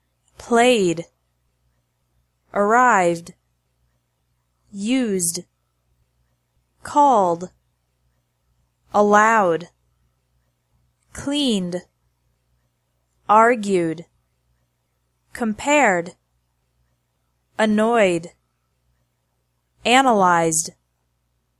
3) -ED is pronounced like D (NO extra syllable)
In all other verbs